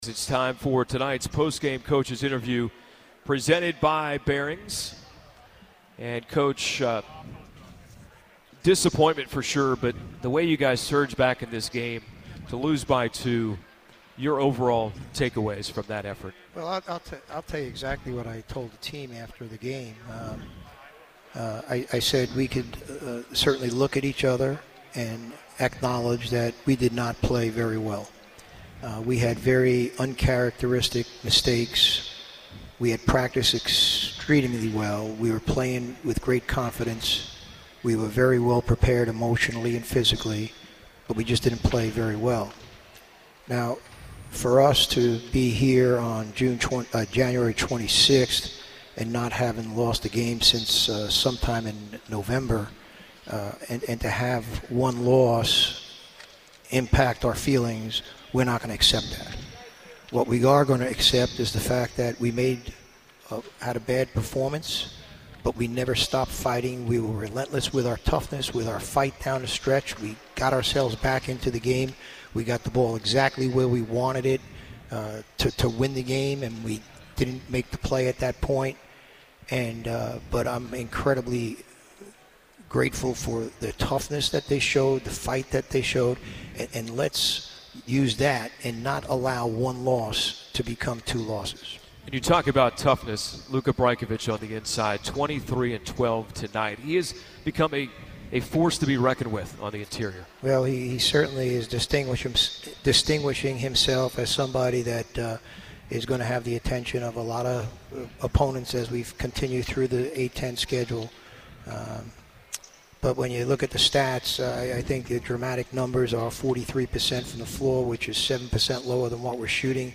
McKillop Postgame Radio Interview